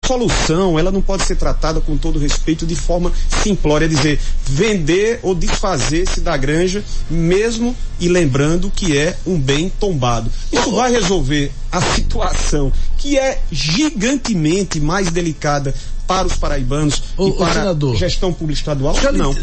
As declarações do parlamentar repercutiram em entrevista ao Arapuan Verdade